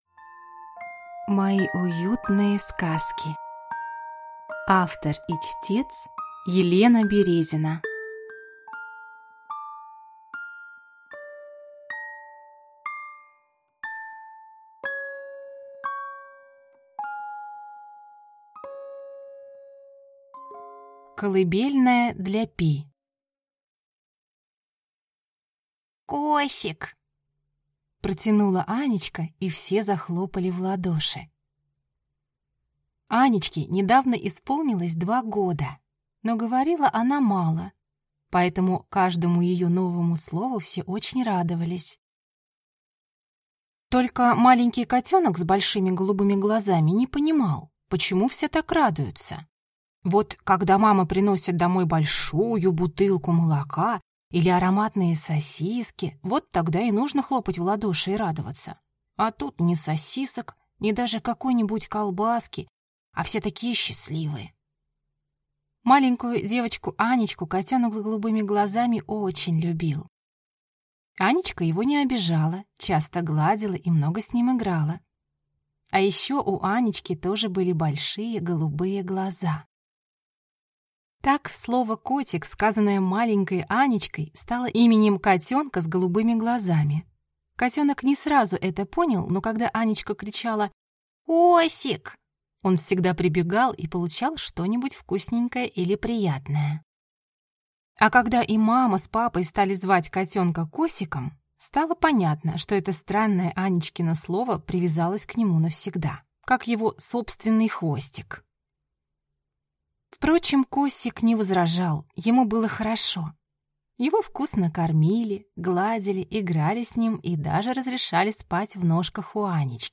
Аудиокнига Мои уютные сказки | Библиотека аудиокниг